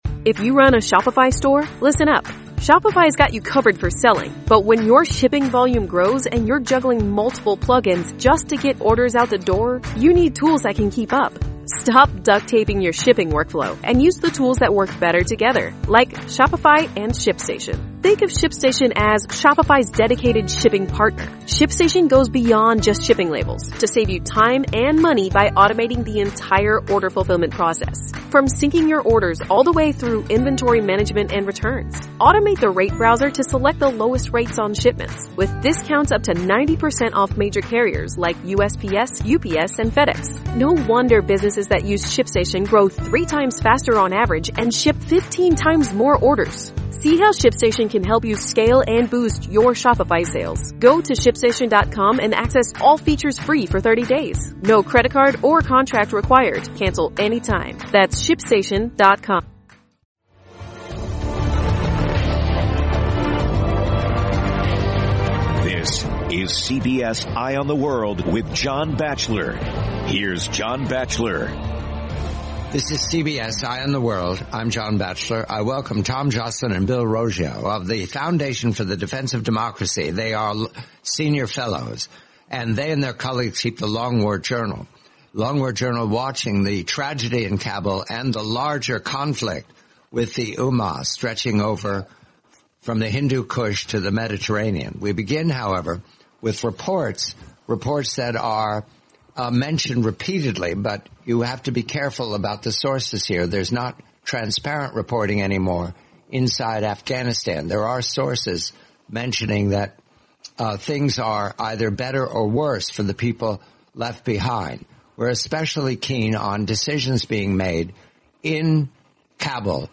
forty-minute interview